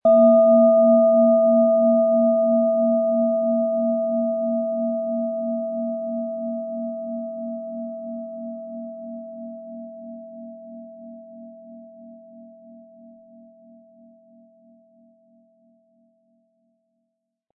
Durch die traditionsreiche Herstellung hat die Schale stattdessen diesen einmaligen Ton und das besondere, bewegende Schwingen der traditionellen Handarbeit.
Der passende Schlegel ist umsonst dabei, er lässt die Schale voll und harmonisch tönen.
SchalenformBihar
MaterialBronze